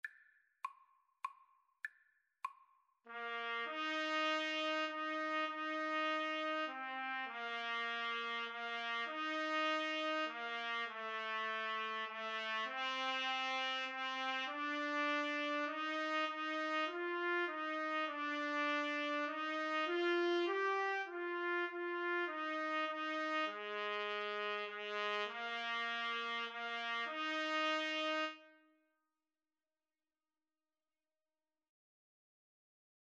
3/4 (View more 3/4 Music)
Trumpet Duet  (View more Easy Trumpet Duet Music)
Classical (View more Classical Trumpet Duet Music)